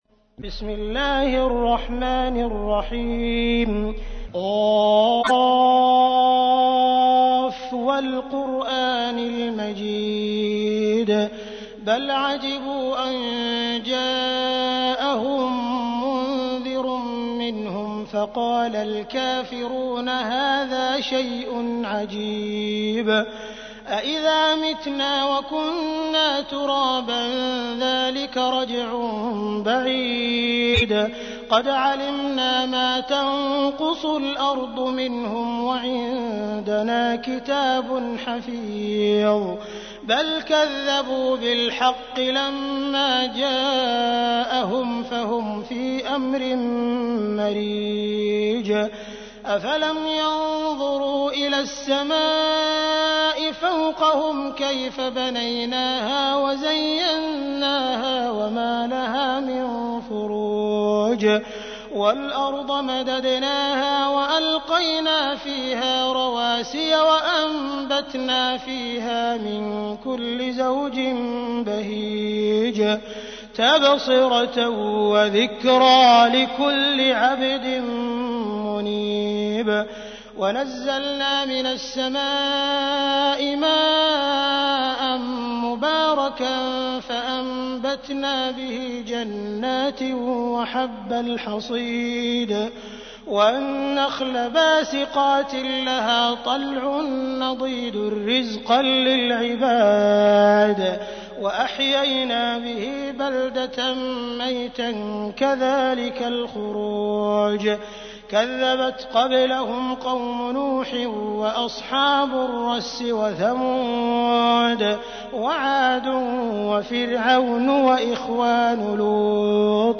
تحميل : 50. سورة ق / القارئ عبد الرحمن السديس / القرآن الكريم / موقع يا حسين